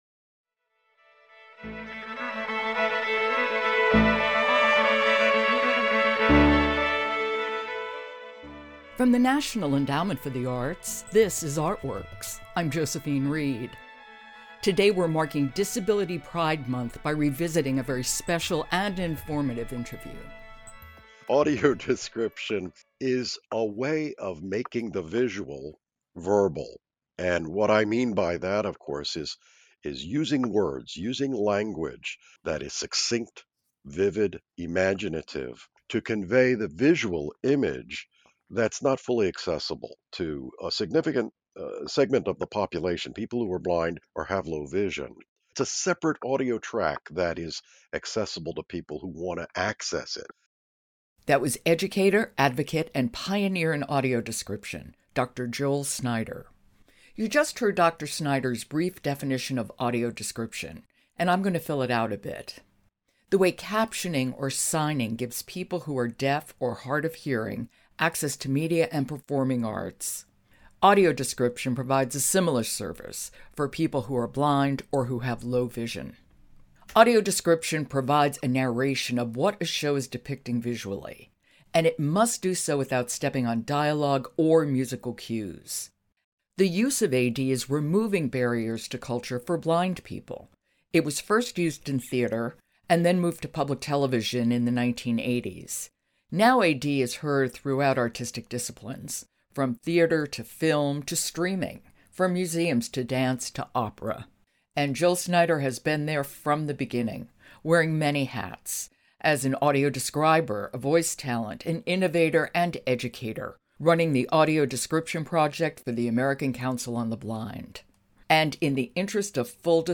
In honor of Disability Pride Month, we are revisiting a conversation